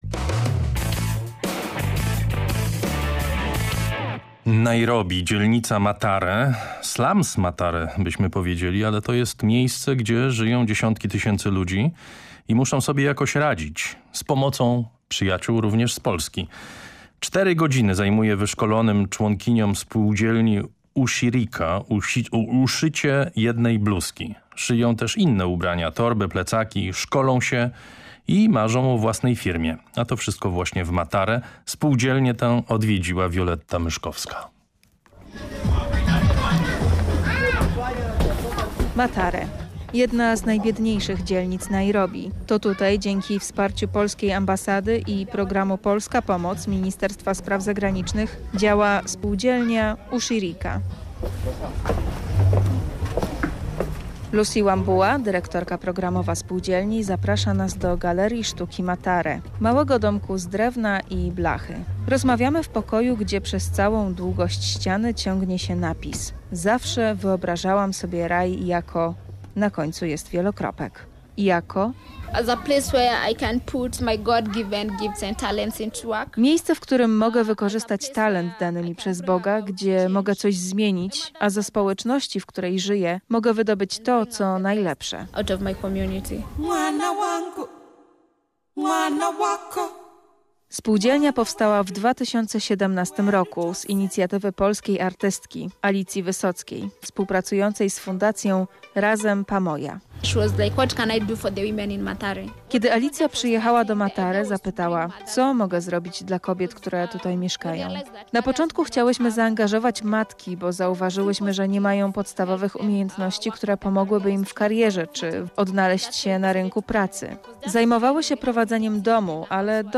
Reportaż oraz fotorelacja na stronach Raportu o stanie świata Programu Trzeciego PR